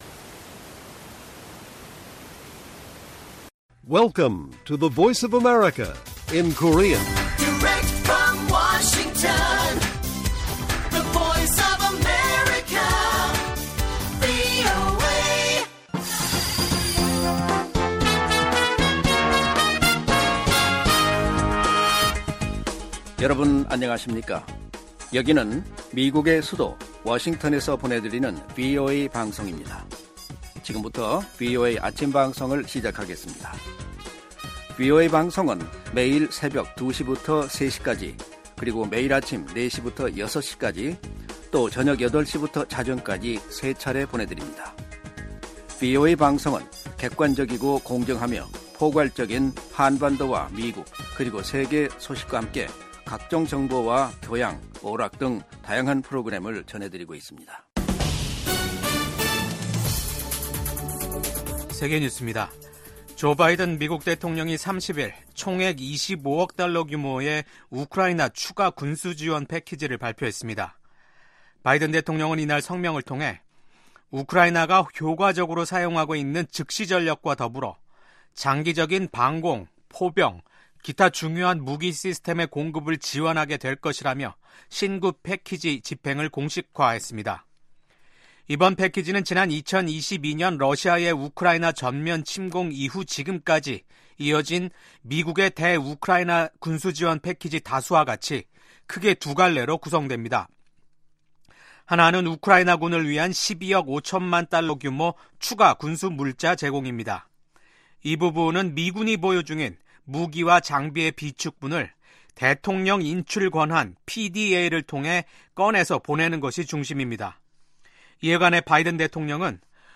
세계 뉴스와 함께 미국의 모든 것을 소개하는 '생방송 여기는 워싱턴입니다', 2024년 12월 31일 아침 방송입니다. 지미 카터 전 미국 대통령이 29일 향년 100세를 일기로 타계했습니다. 한국 전남 무안 국제공항에서 착륙을 시도하던 여객기가 활주로 담장과 충돌해 폭발하면서 179명이 사망하는 사고가 발생했습니다. 독일 대통령이 의회를 해산하고 내년 2월 23일 조기 총선을 실시할 것을 명령했습니다.